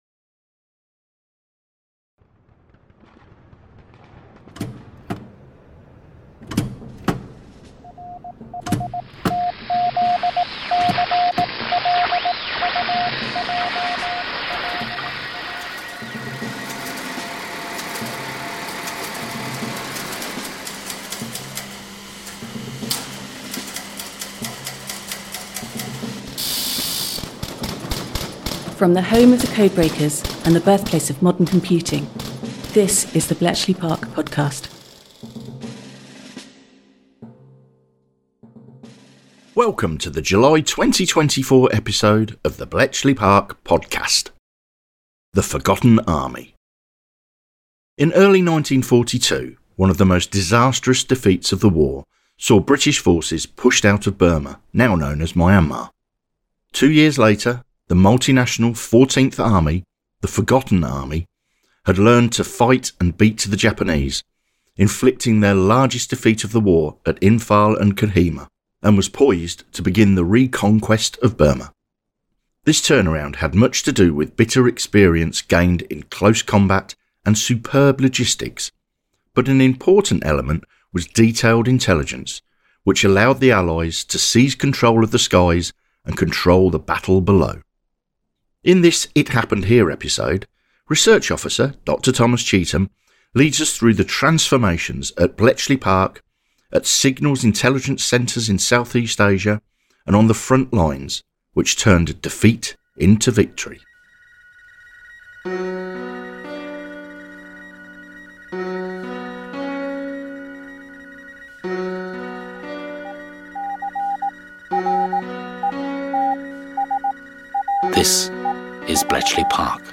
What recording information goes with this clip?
This episode features the following from our Oral History archive: